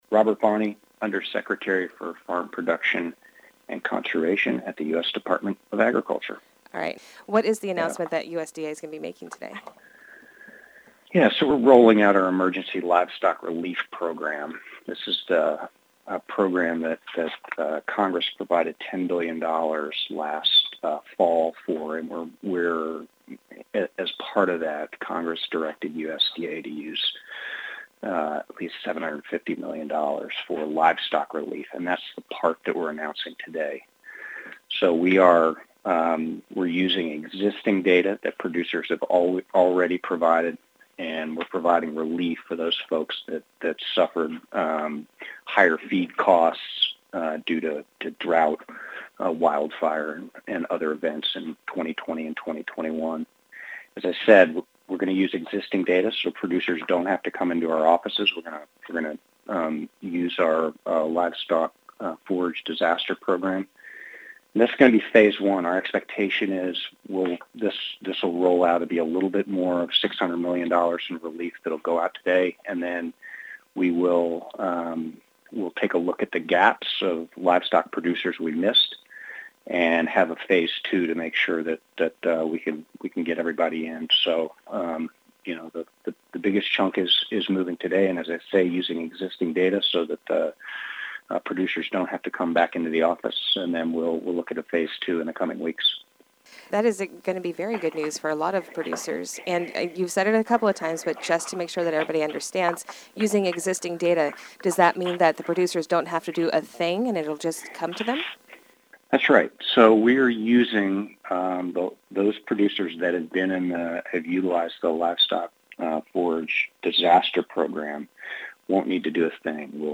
USDA Undersecretary for Farm Production and Conservation, Robert Bonnie spoke with AgNet Media to make the announcement to our listeners.